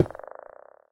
Minecraft Version Minecraft Version 1.21.5 Latest Release | Latest Snapshot 1.21.5 / assets / minecraft / sounds / block / lodestone / place3.ogg Compare With Compare With Latest Release | Latest Snapshot
place3.ogg